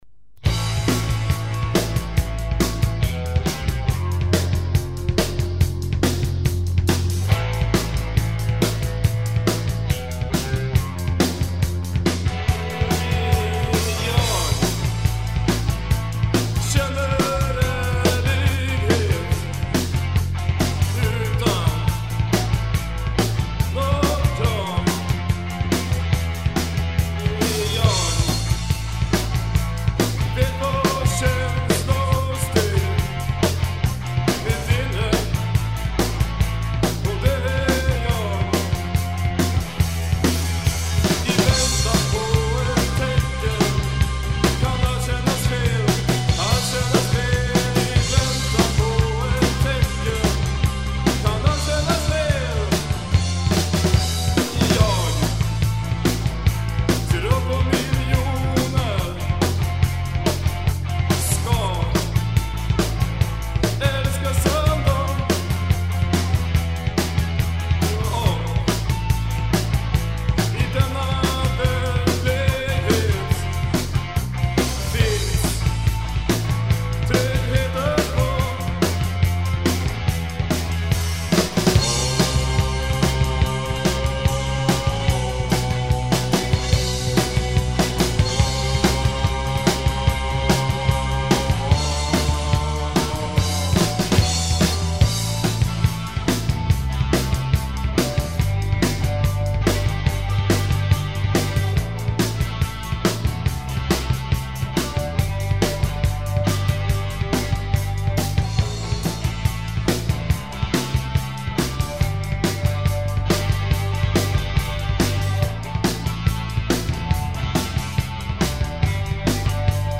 Bass
Guitar
Voice
Drums